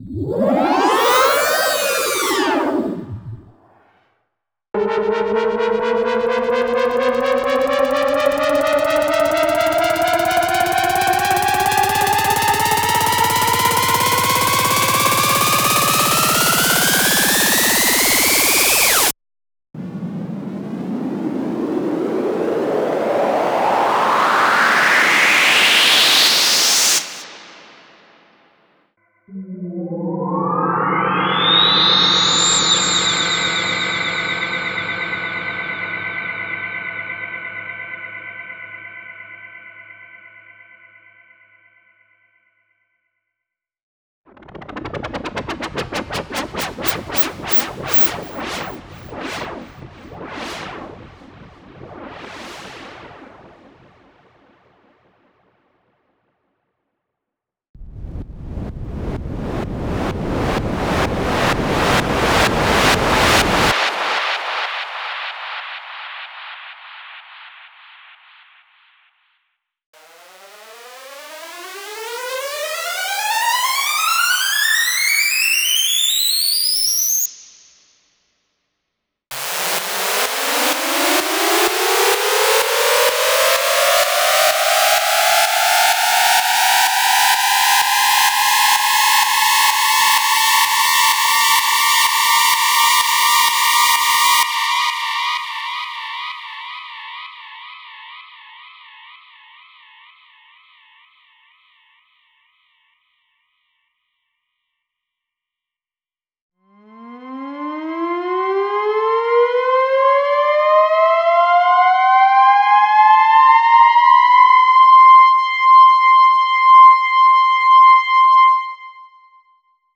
PRE-IMPACT.wav